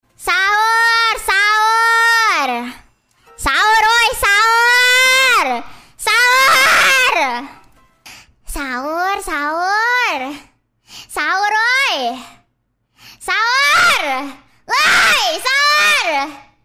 Kategori: Nada dering